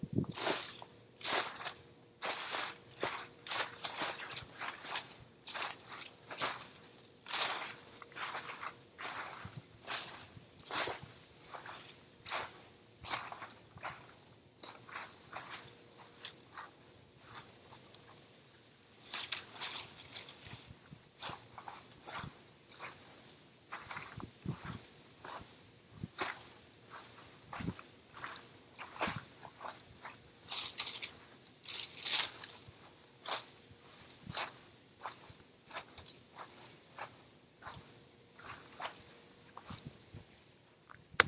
现场记录1 " 叶子
描述：使用电容心形麦克风对叶片进行不良记录。
Tag: 萧萧 噪声 高音